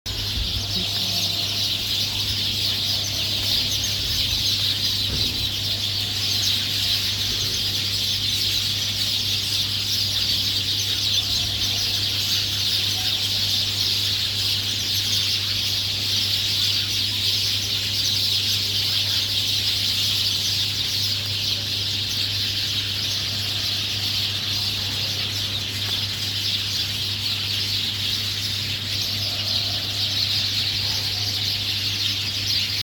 Birds Chirping, Chirping Birds, Birds Twittering, Loud Sound Effect Download: Instant Soundboard Button